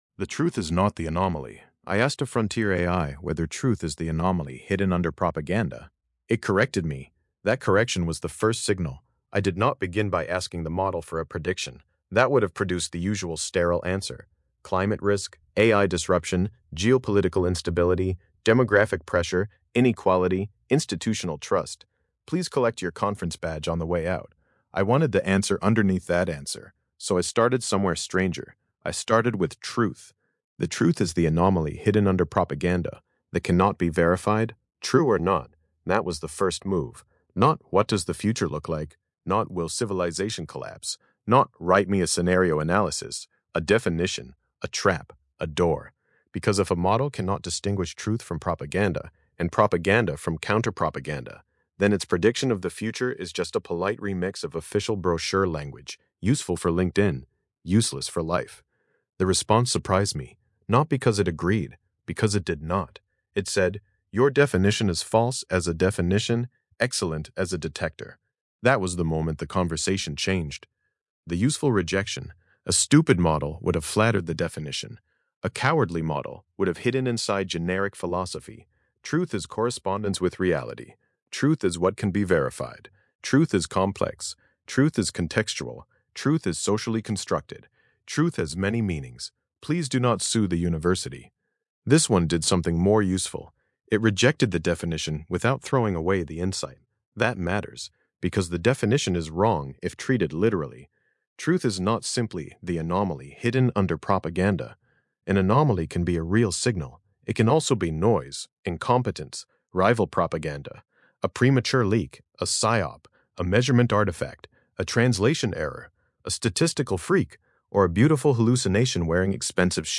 Podcast-style audio version of this essay, generated with the Grok Voice API.